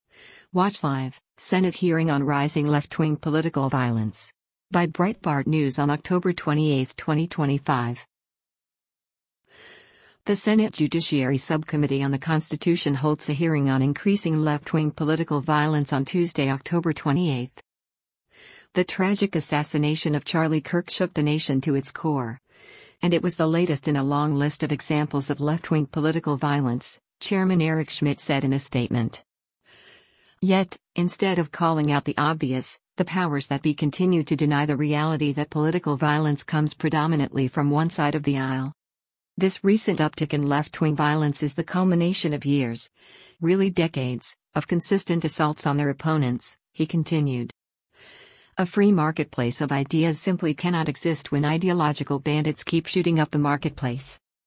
The Senate Judiciary Subcommittee on the Constitution holds a hearing on increasing left-wing political violence on Tuesday, October 28.